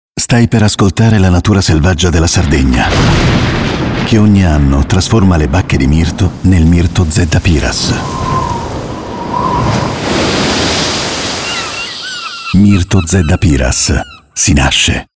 Spot istituzionale